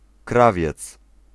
Ääntäminen
Synonyymit costume tailleur Ääntäminen France: IPA: [tɑ.jœʁ] Tuntematon aksentti: IPA: /ta.jœʁ/ Haettu sana löytyi näillä lähdekielillä: ranska Käännös Ääninäyte 1. krawiec {m} 2. krawcowa {f} Suku: m .